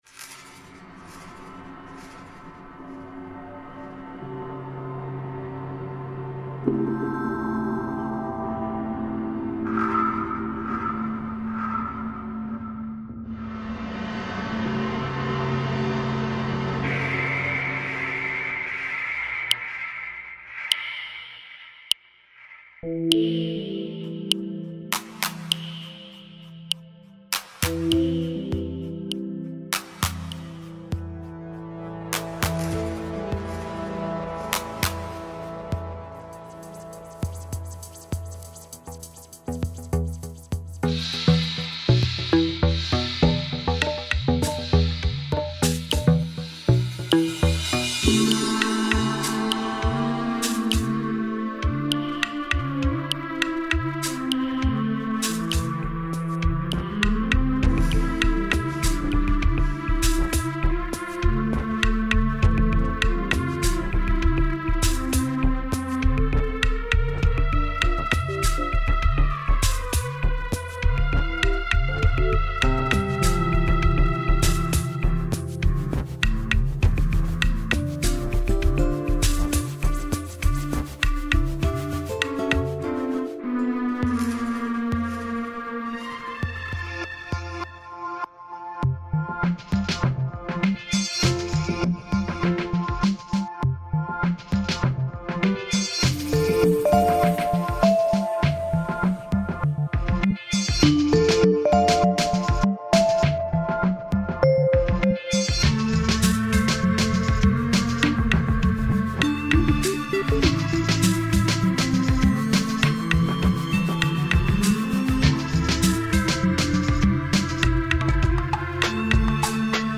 мелодія під цей вірш
мелодія , на програмі писали.